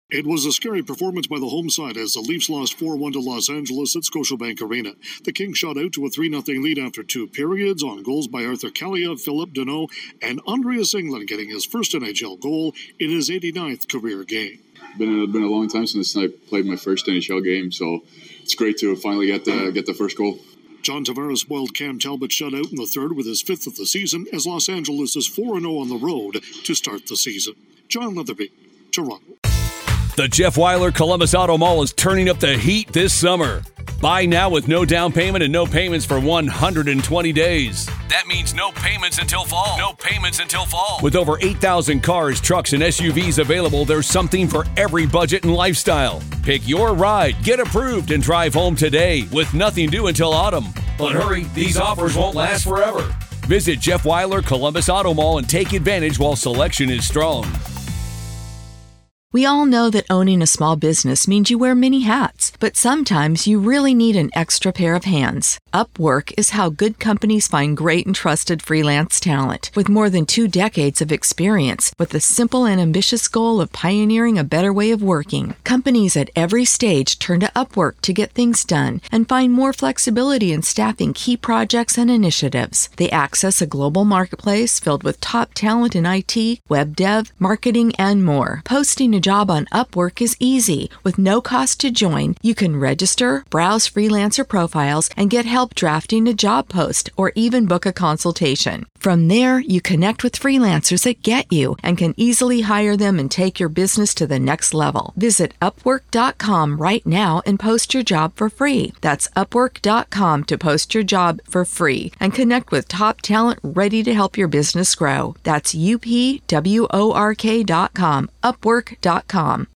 The Kings give the Maple Leafs the trick instead of the treat on Halloween night. Correspondent